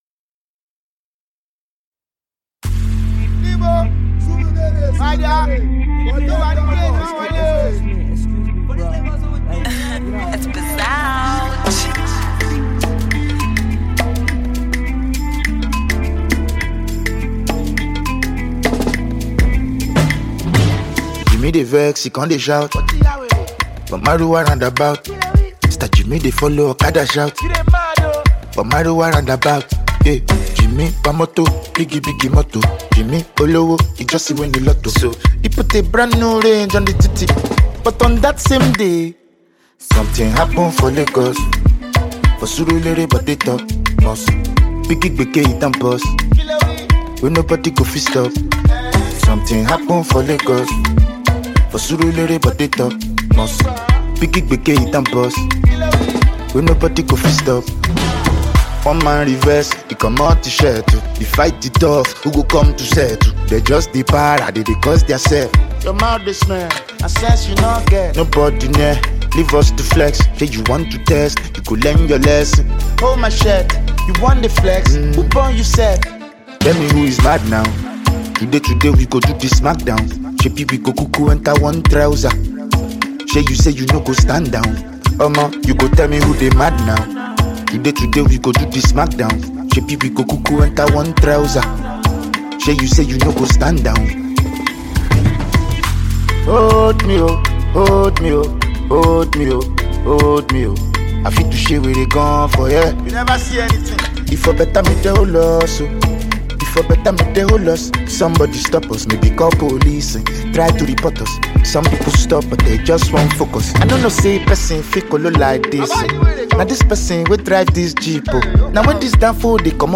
a dance and groovy song